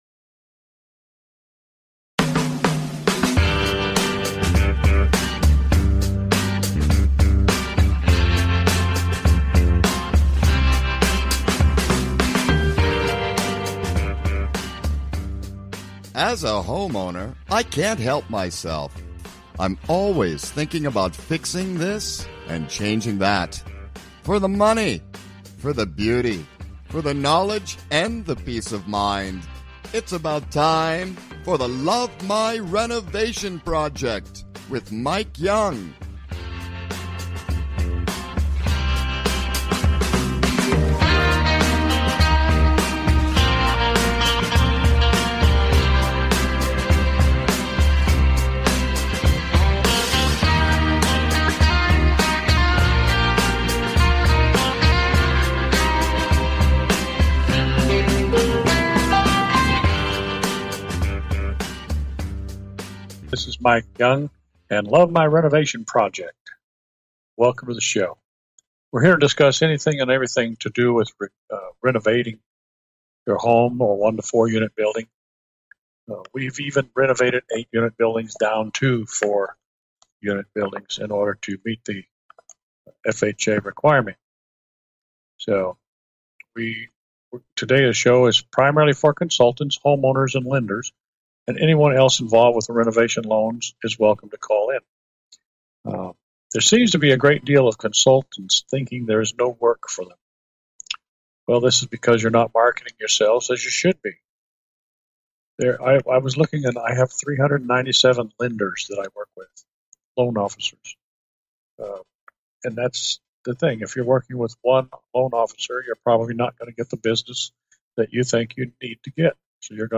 Please consider subscribing to this talk show.